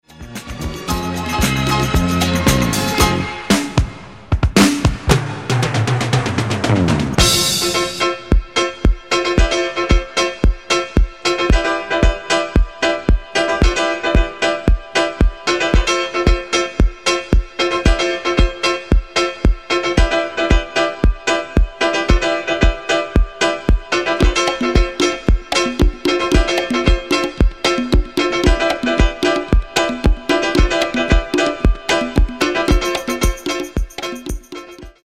Genere:   Disco | Funky | Soul